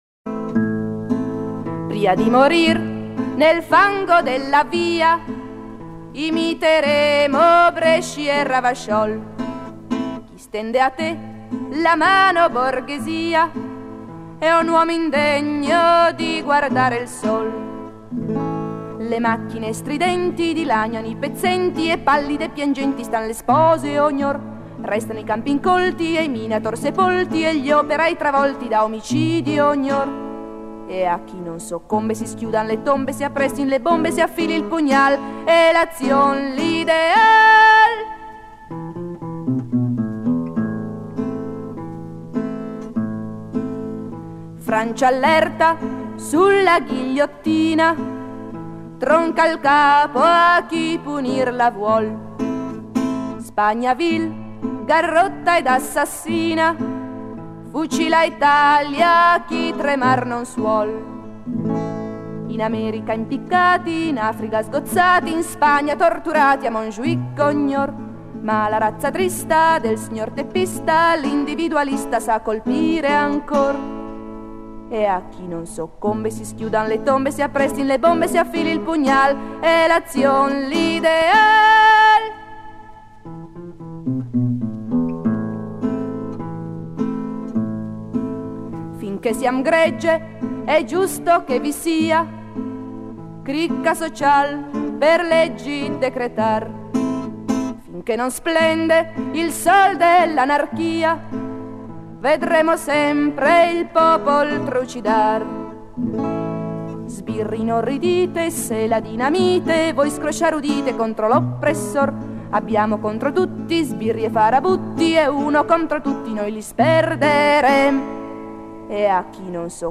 canzone anarchica